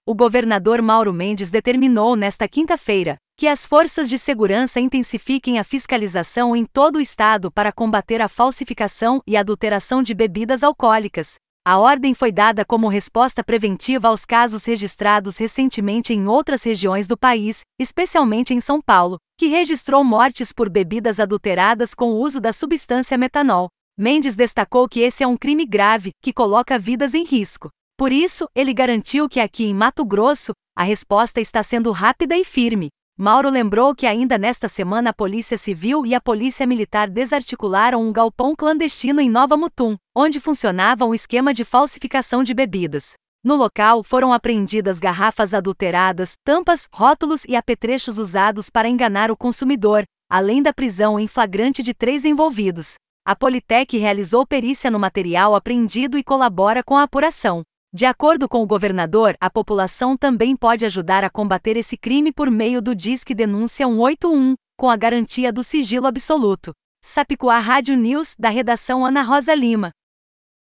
Boletins de MT 03 out, 2025